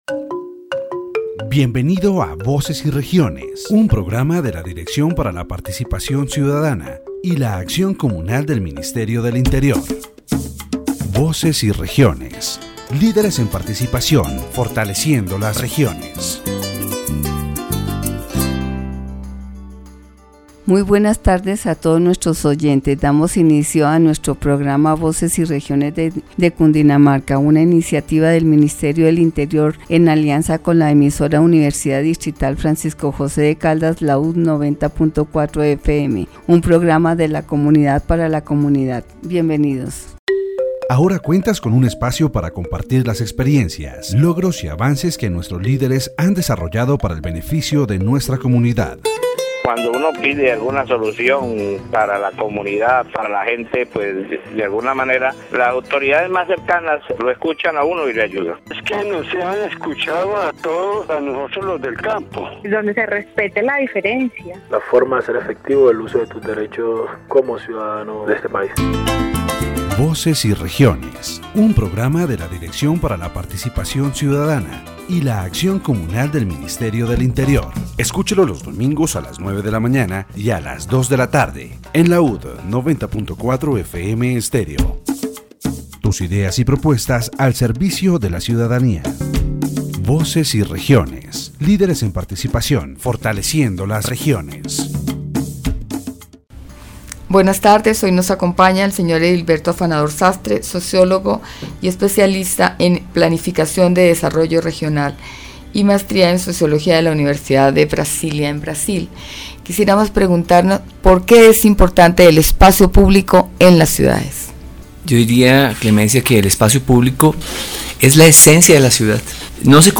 The radio program "Voices and Regions" of the Directorate for Citizen Participation and Communal Action of the Ministry of the Interior focuses on the importance of public space in cities and the problem of street vendors.